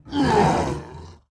Index of /App/sound/monster/orc_general
fall_1.wav